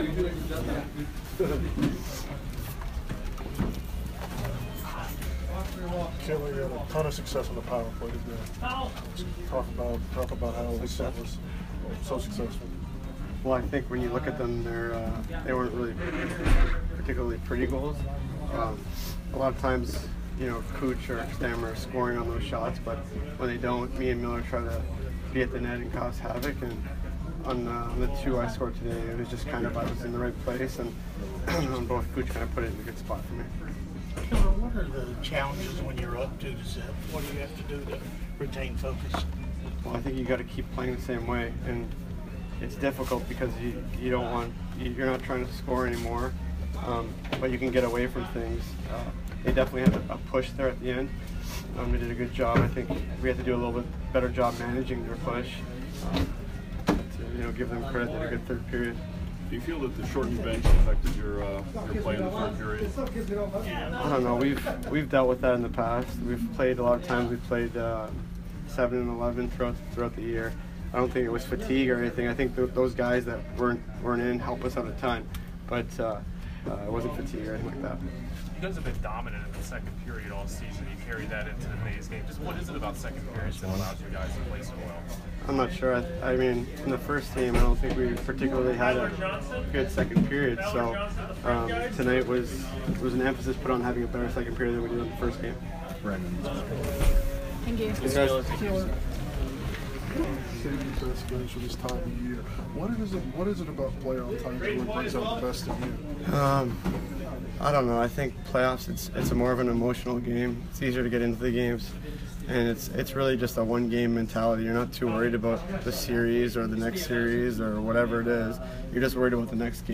Alex Killorn post-game 4/14